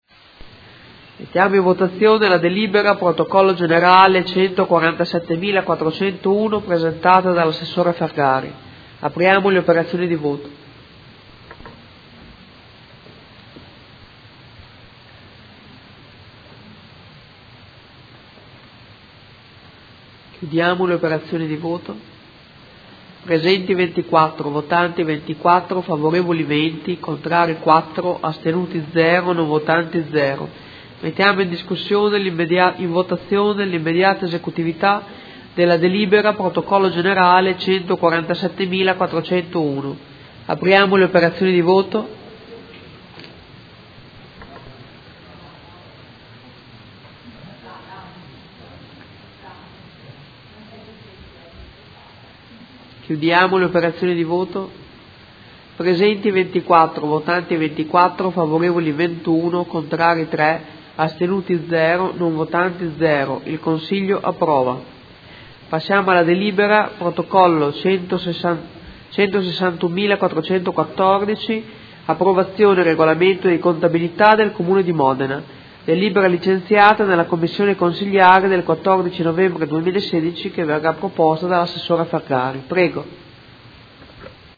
Presidentessa
Audio Consiglio Comunale